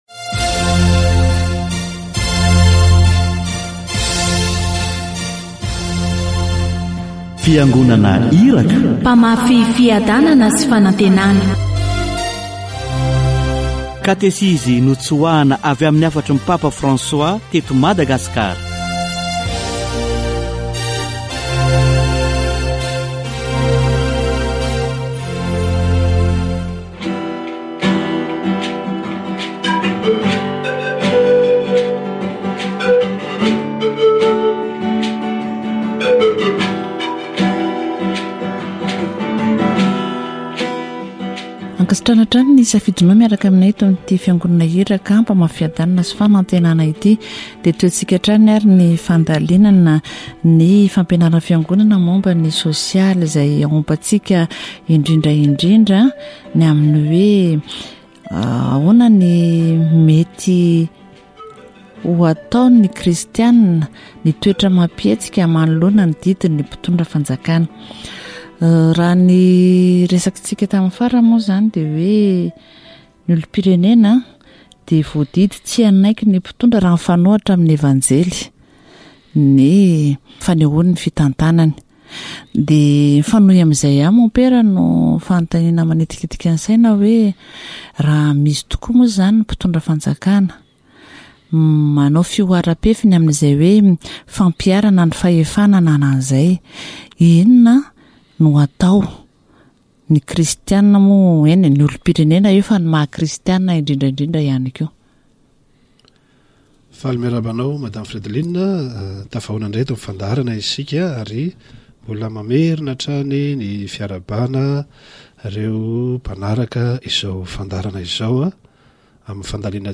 Les citoyens peuvent protéger leurs droits et ceux de leurs concitoyens en s'opposant aux abus de pouvoir tels que définis par la loi et l'Evangile. Catéchèse sur le gouvernement et ses devoirs